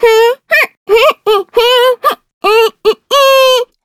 Taily-Vox_Hum.wav